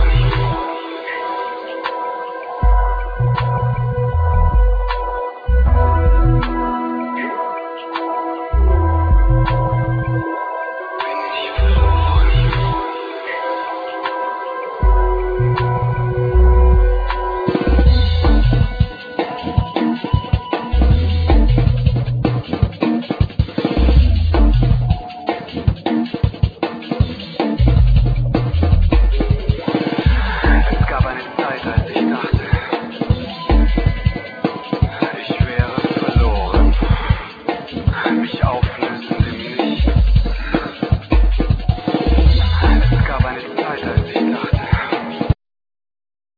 Trumpet,Rhodes
Guiatr,Noises
Bass
Beats
Spoken words
Samples,Programming